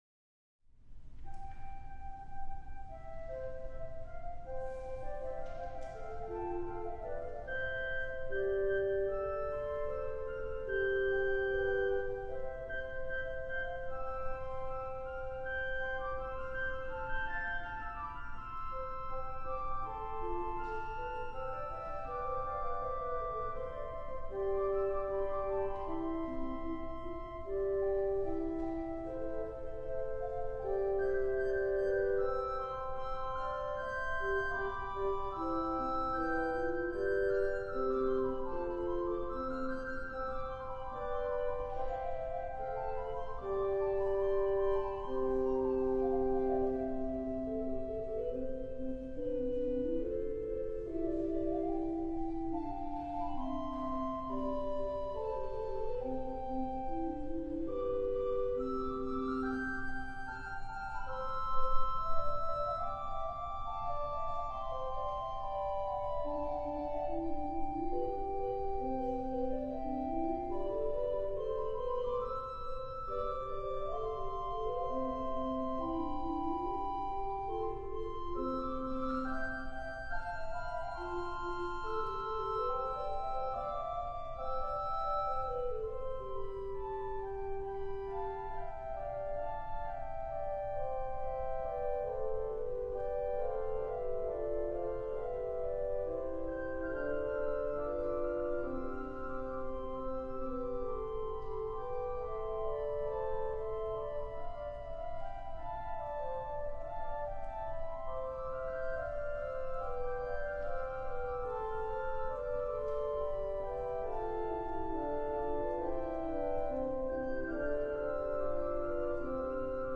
Live in Concert - The Quimby Pipe Organ of Fourth Presbyterian Church